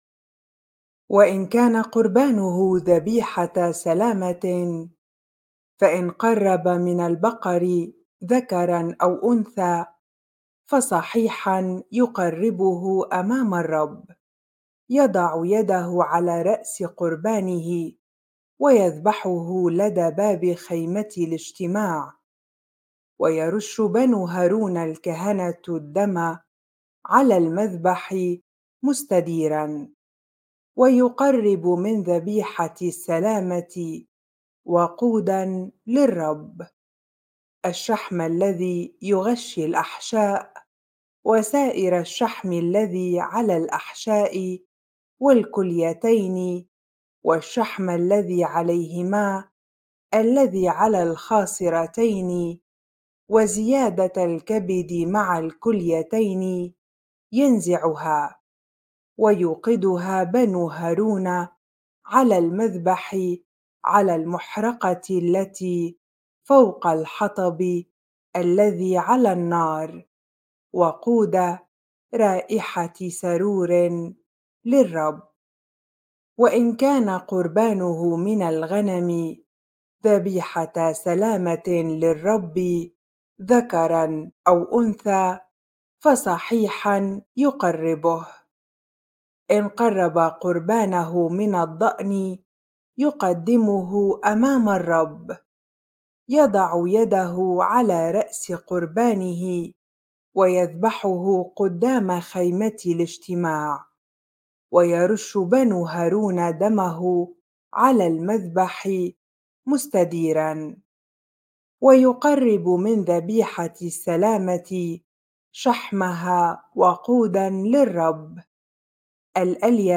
bible-reading-leviticus 3 ar